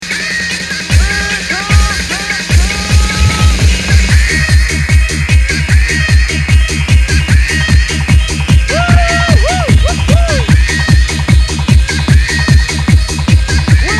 the vocal says in my house if thats any help